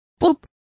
Complete with pronunciation of the translation of pub.